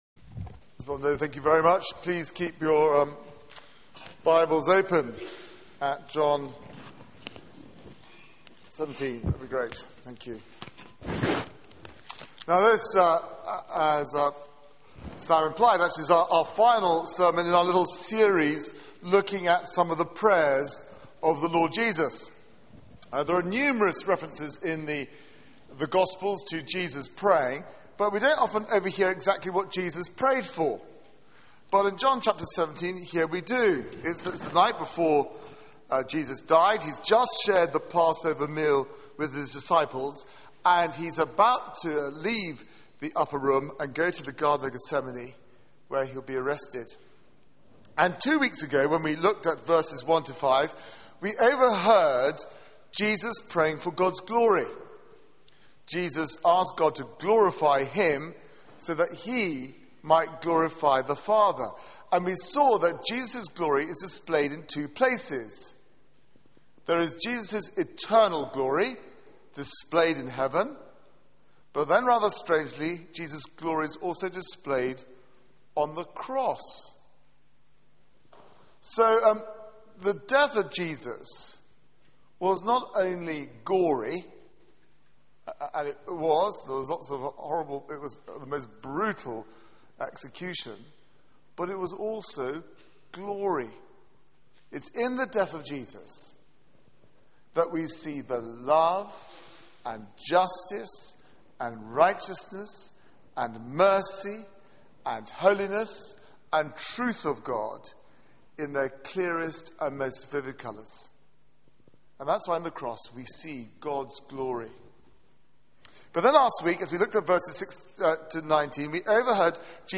Media for 6:30pm Service on Sun 20th May 2012 18:30 Speaker
Series: Praying with Jesus Theme: What Jesus prays for you Sermon